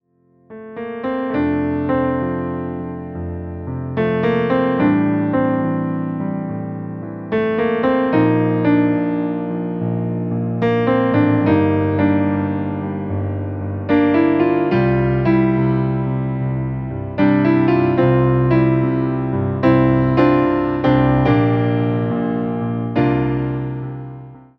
Nastrojowy podkład fortepianowy
Wersja demonstracyjna:
72 BPM
F – dur